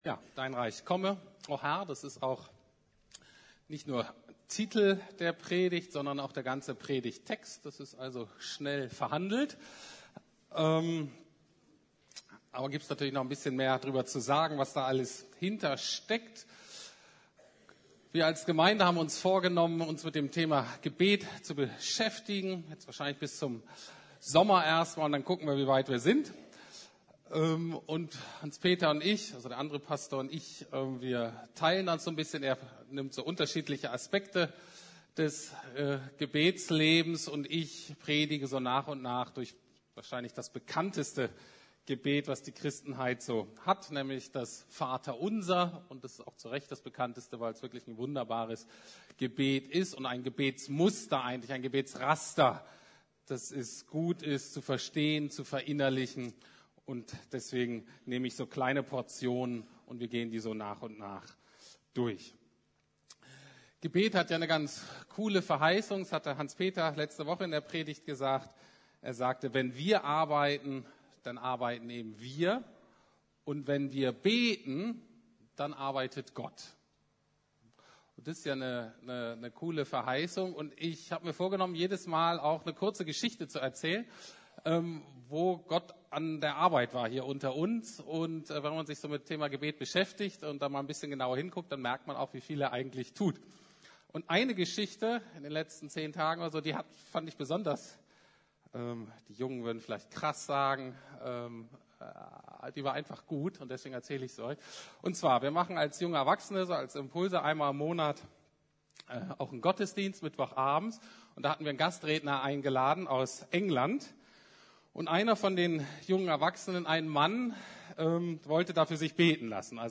Dein Reich komme! ~ Predigten der LUKAS GEMEINDE Podcast